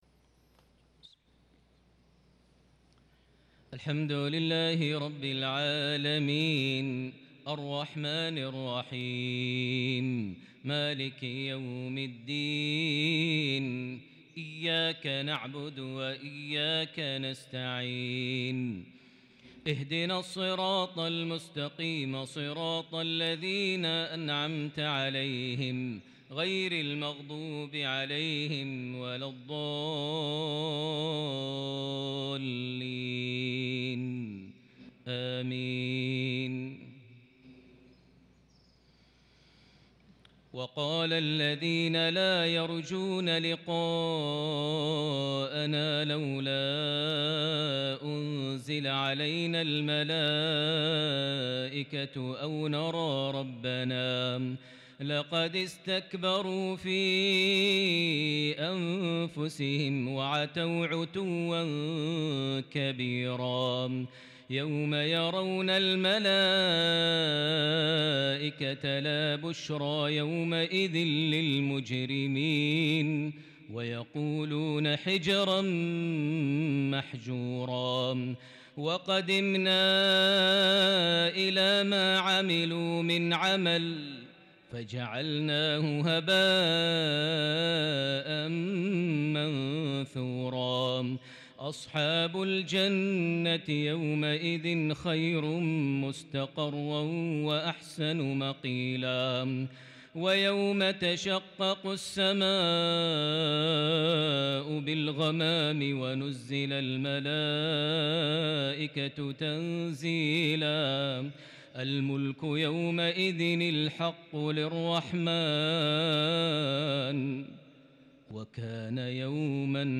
صلاة المغرب من سورة الفرقان | 29 محرم 1443هـ | mghrip 6-9- 2021 prayer from Surah Al-Furqan 21-33 > 1443 🕋 > الفروض - تلاوات الحرمين